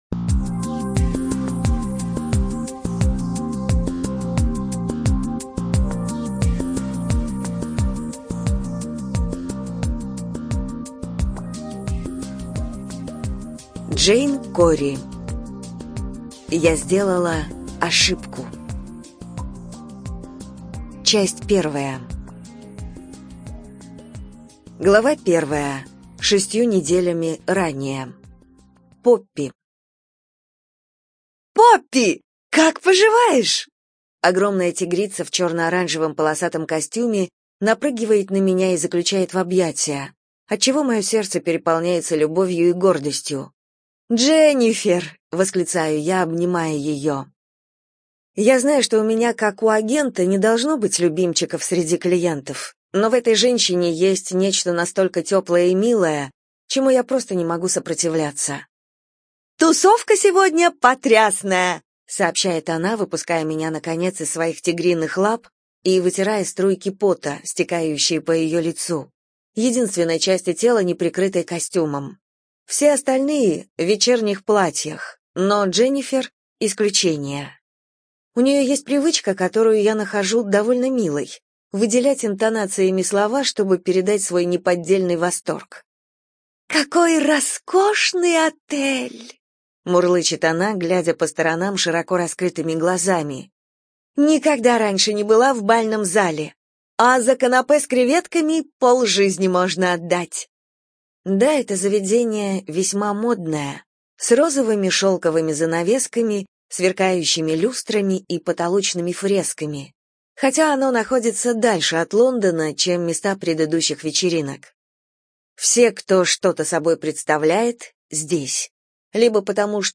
Эту и другие книги нашей библиотеки можно прослушать без использования компьютера с помощью Android-приложения или тифлофлешплеера с поддержкой онлайн-доступа.